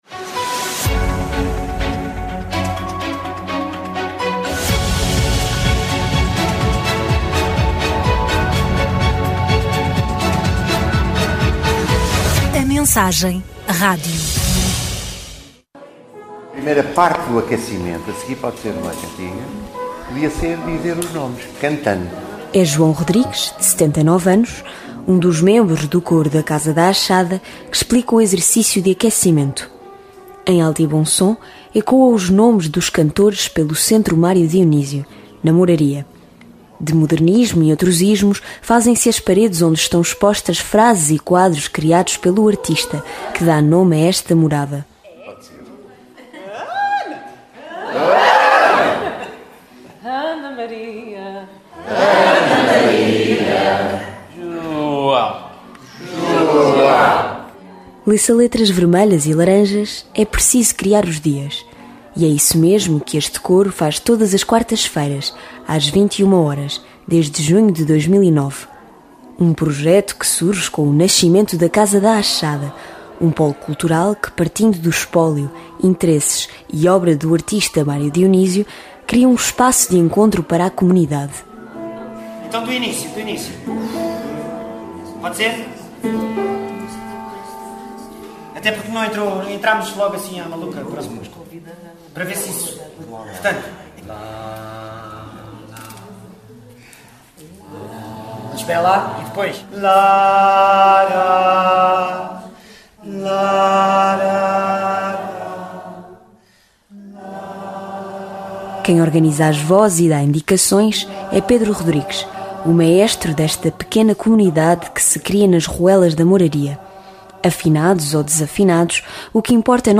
Messagem-cantar-en-grupo.mp3